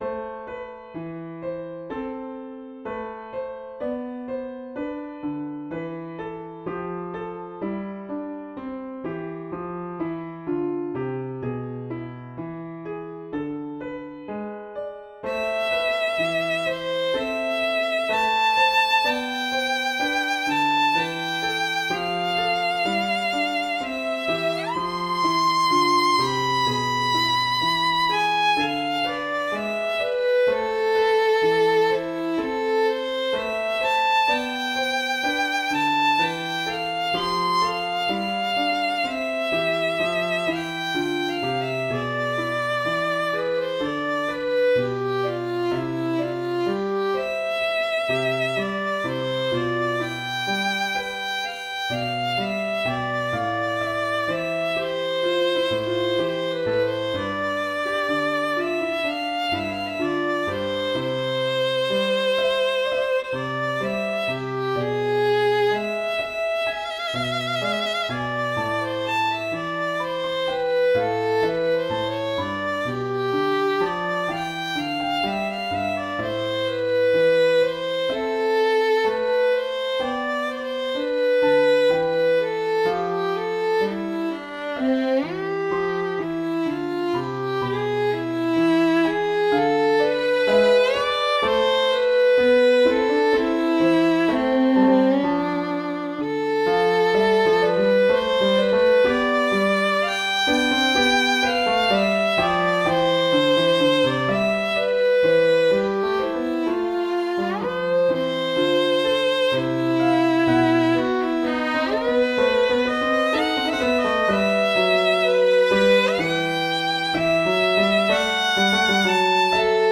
A Minor Sonata for Violin and Piano - Movement I - Larghetto
Accented passing notes, leaping from diss...